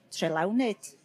Pentref bychan yng nghymuned Trelawnyd a Gwaenysgor, Sir y Fflint, Cymru, yw Trelawnyd ("Cymorth – Sain" ynganiad ).